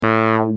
Free MP3 vintage Korg PS3100 loops & sound effects 7